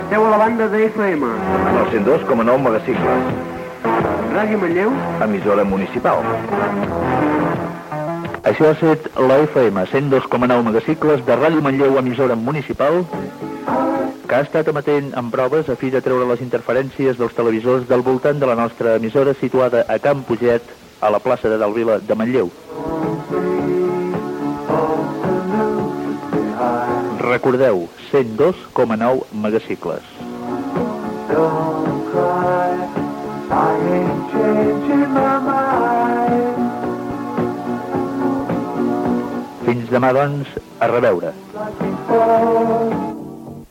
Emissió inaugural en proves. Identificació de l'emissora, freqüència, anunci que aviat l'emissió serà en estereofonia, visites rebudes, publicació "Manlleu Comunicació" amb el resultat de les eleccions, avís que l'emissió és en proves, identificació, avís del programa informatiu de les eleccions municipals, identificació.
FM